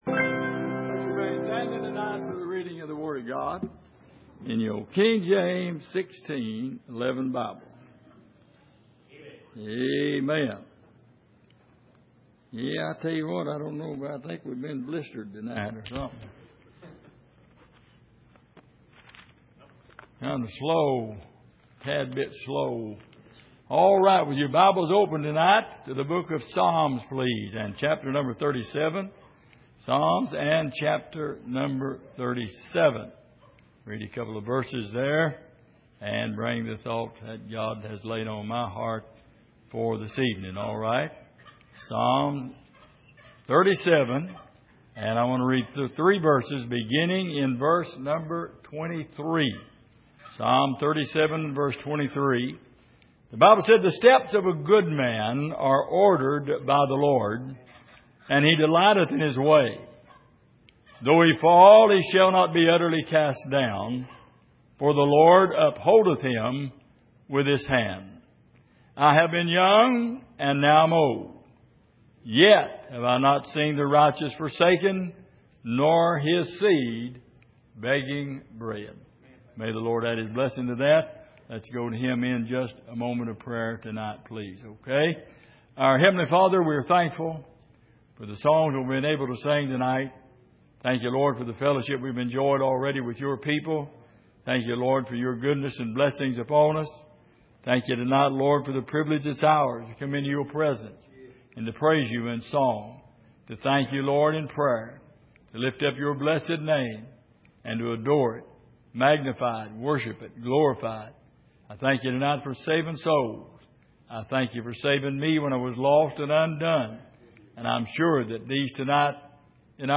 Passage: Psalm 37:23-25 Service: Sunday Evening